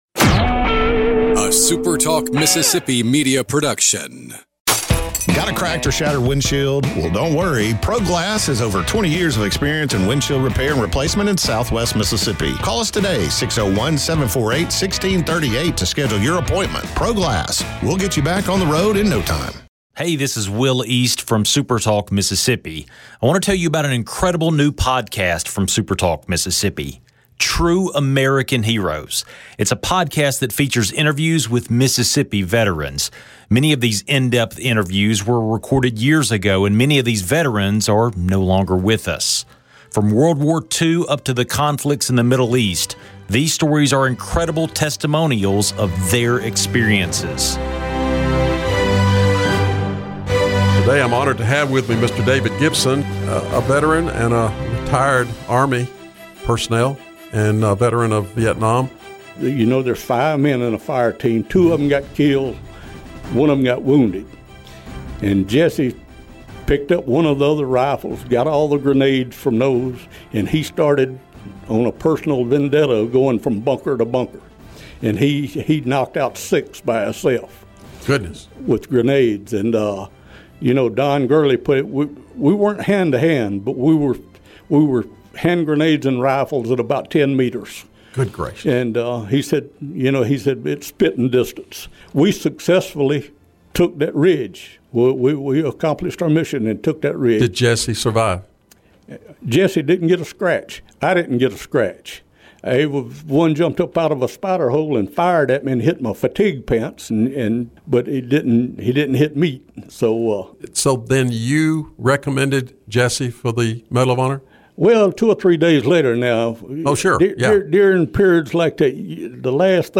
True American Heroes from SuperTalk Mississippi is a podcast that features interviews with Mississippi veterans. Many of these in-depth interviews were recorded years ago and many of these veterans are no longer with us. From World War Two up until the conflicts in the Middle East, these stories are incredible testimonials of their experiences.